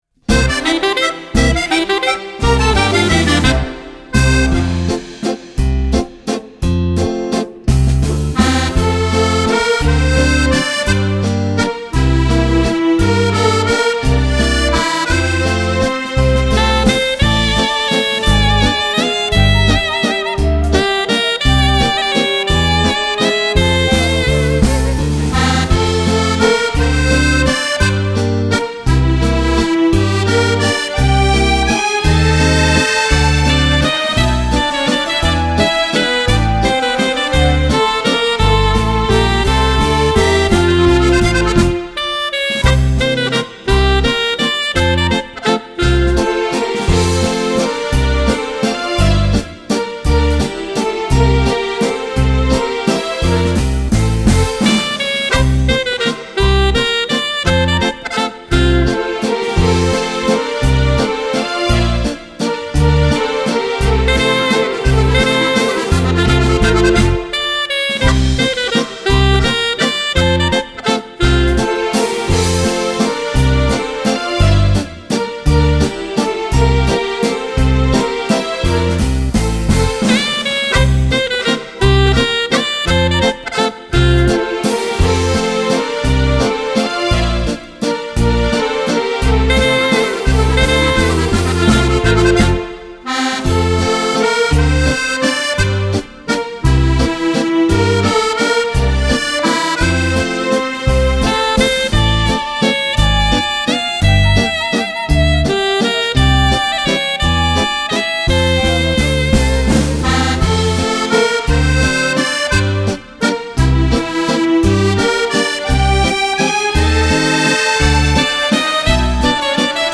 Genere: Valzer viennese